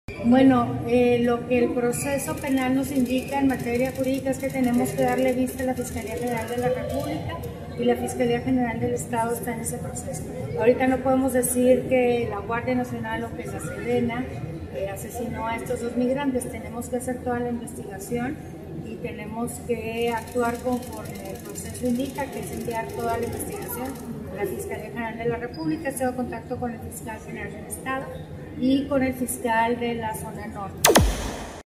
AUDIO: MARÍA EUGENIA CAMPOS , GOBERNADORA DEL ESTADO DE CHIHUAHUA Chihuahua, Chih.- La gobernadora, María Eugenia Campos , pidió a la ciudadanía esperar las instigaciones a cerca del presunto ataque en contra de ocho migrantes por parte de elementos de la Guardia Nacional (GN), mientras se dirigían hacía la frontera con la finalidad de cruzarla e internarse en los Estados Unidos.